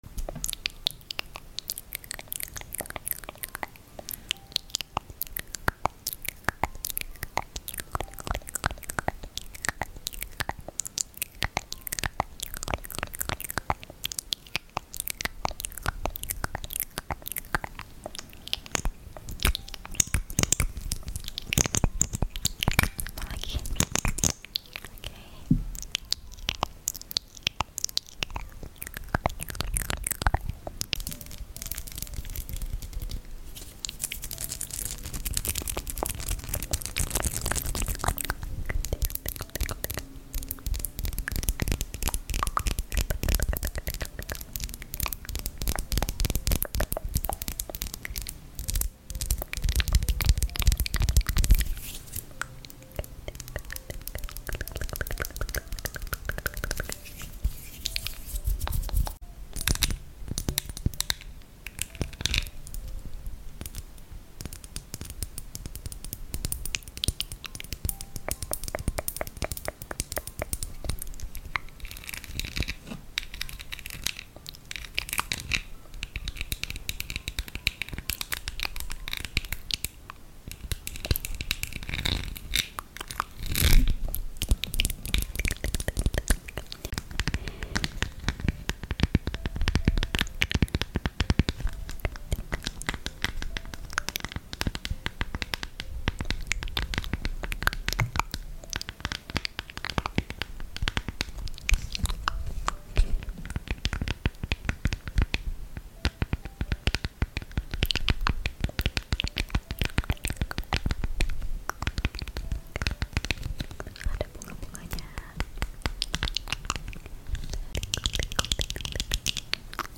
Mouthsound And Tapping